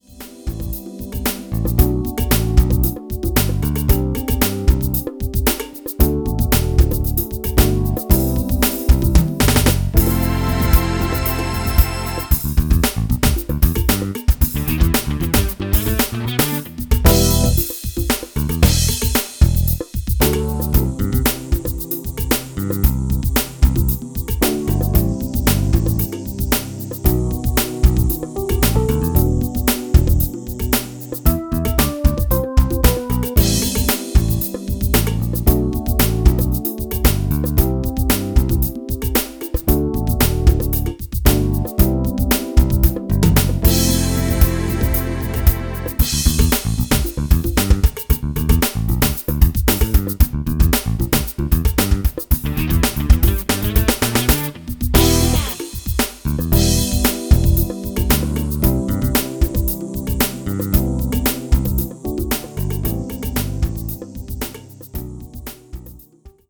No horn section.
Guitar included.
Key of E Minor